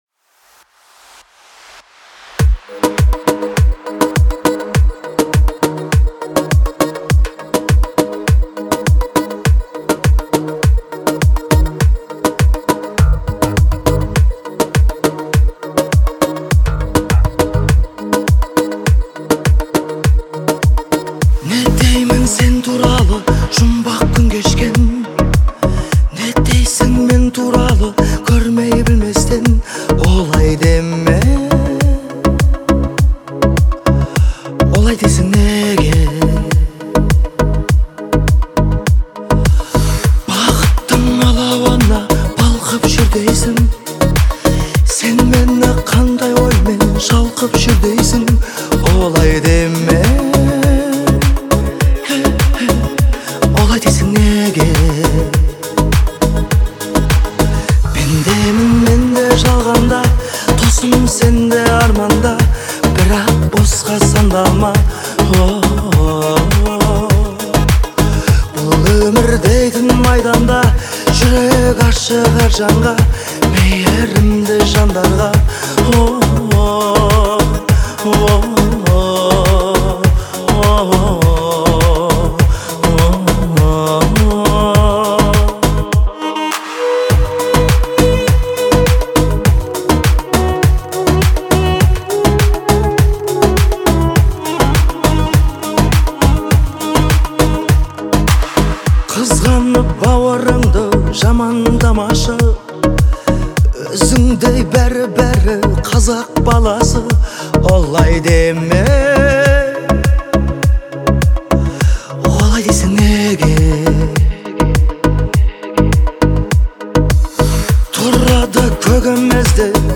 известный своим мощным вокалом и харизмой